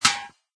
metal3.mp3